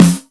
• Strong Bottom End Snare F Key 264.wav
Royality free snare sound tuned to the F note. Loudest frequency: 1692Hz
strong-bottom-end-snare-f-key-264-gLH.wav